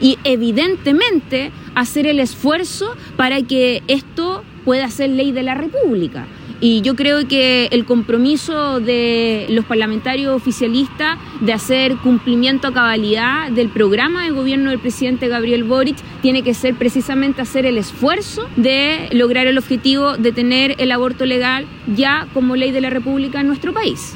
Al respecto, la diputada Daniela Cicardini (PS) sostuvo que, a pesar de todo, el oficialismo debe hacer sus esfuerzos para sacar el proyecto como ley.